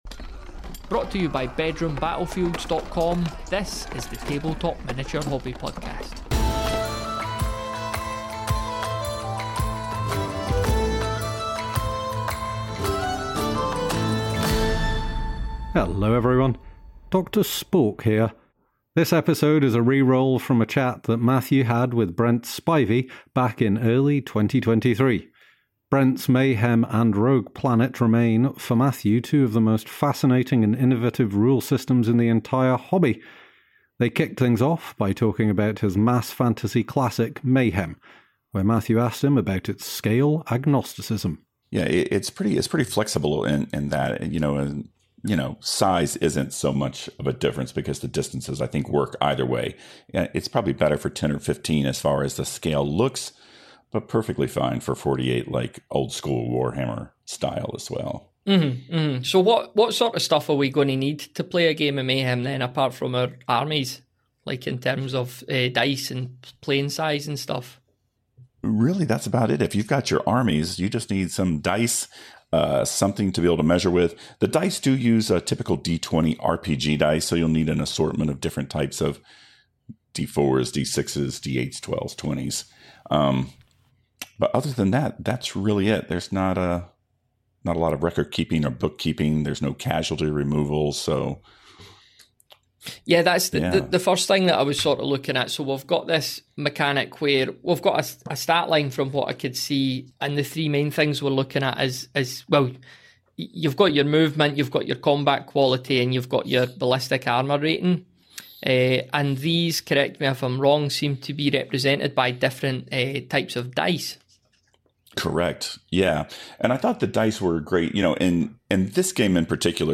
The Bedroom Battlefields Tabletop Miniature Hobby Podcast brings you conversations about collecting, gaming, painting, terrain, and much more - often with a nostalgic twist. We also dive into topics such as productivity, balancing hobby time with other aspects of life, and the overall psychology behind playing with toy soldiers.